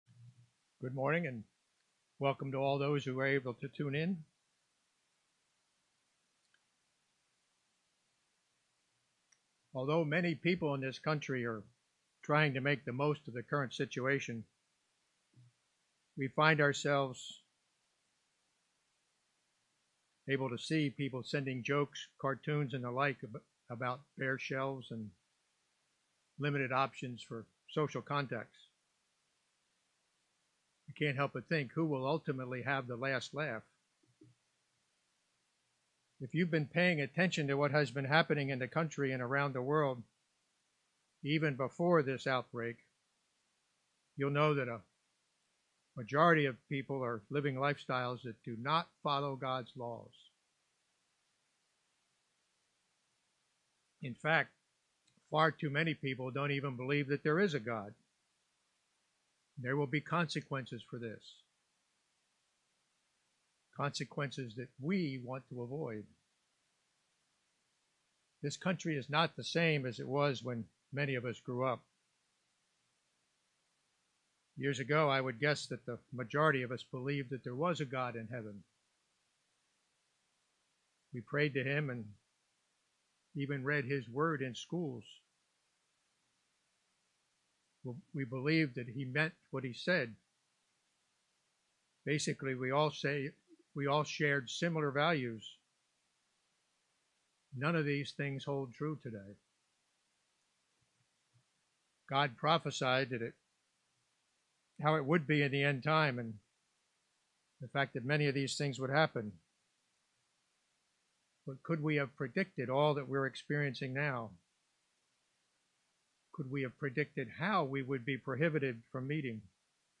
Given in St. Petersburg, FL Tampa, FL